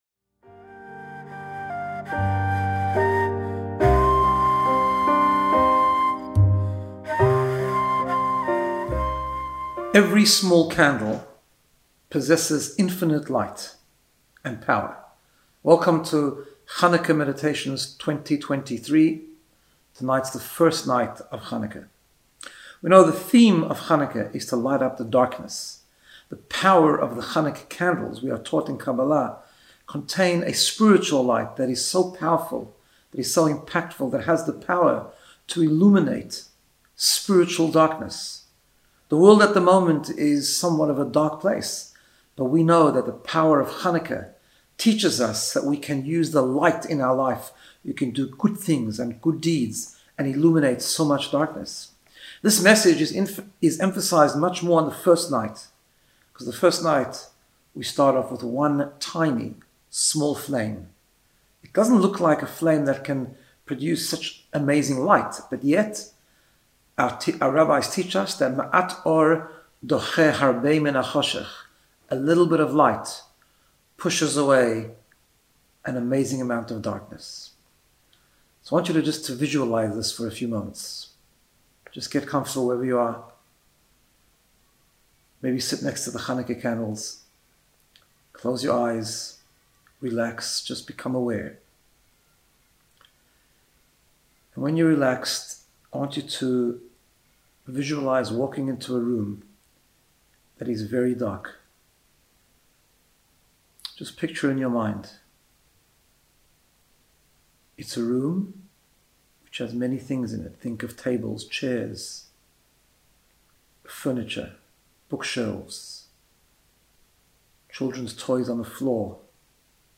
Guided Meditation to get you in the space of Personal Growth and Wellbeing.